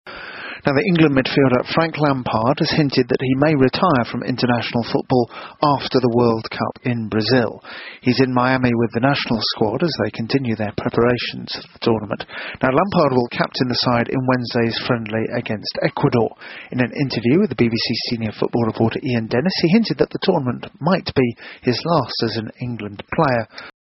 【英音模仿秀】从未想过离开切尔西 听力文件下载—在线英语听力室